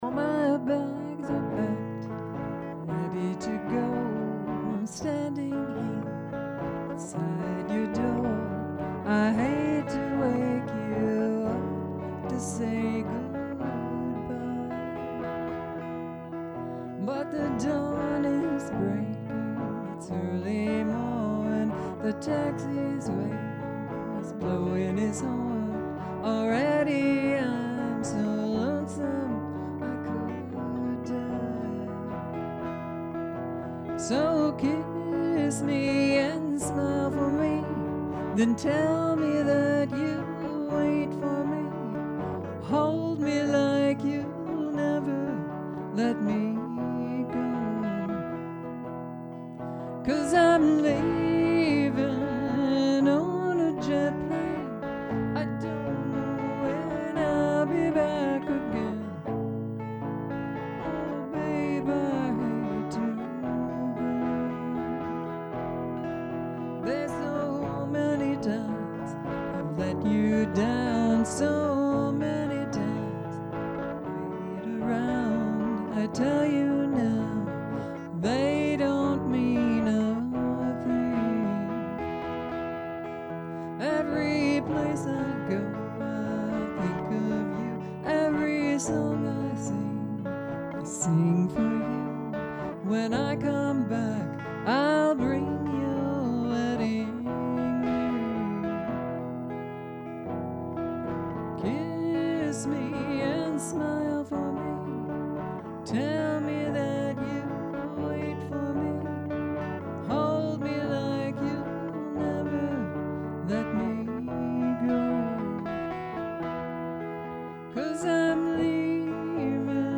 Vocals
Guitar, Bass, Drums
Most Guitar amplified by Peavy tube Amp.